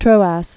(trōăs) also Tro·ad (-ăd)